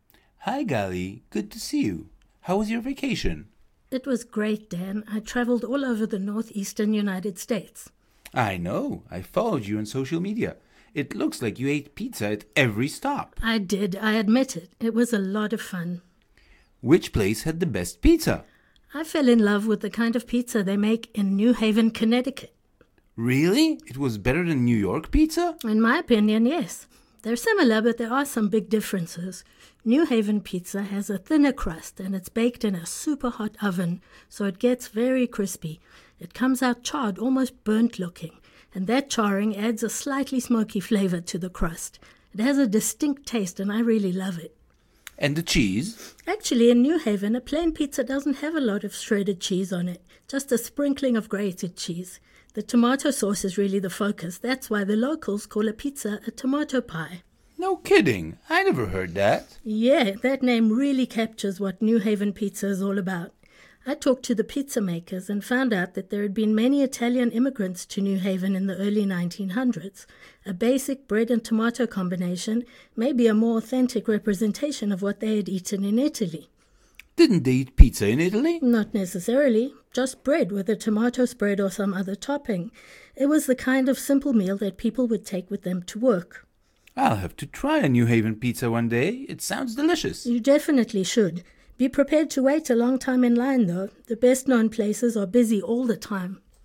• בכמה מקטעי השמע תשמעו אדם אחד מדבר, ובכמה מהם תשמעו שיחה בין שני אנשים - אישה וגבר.